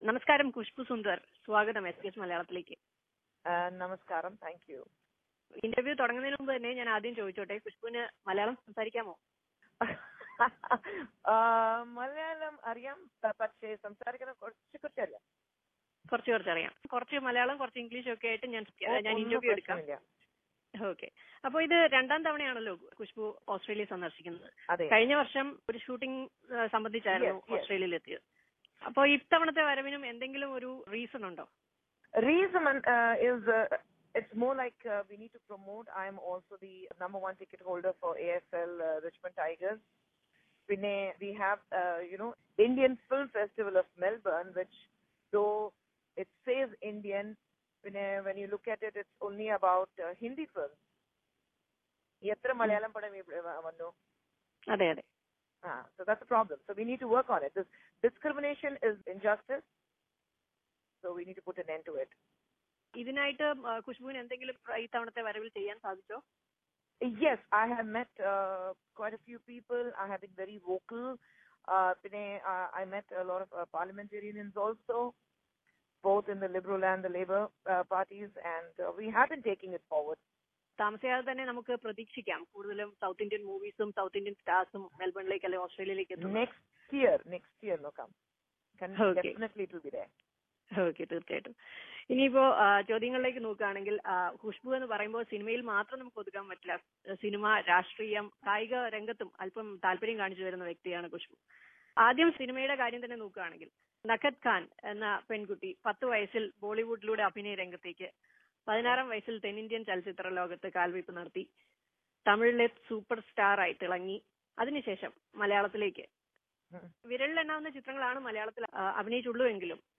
In an exclusive interview to SBS Malayalam, Kushboo, the South Indian movie star turned politician opens up about the her experience in film industry, latest controversies in Malayalam film industry and her plans to return to Malayalam films.